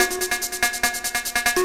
K-6 Percussion.wav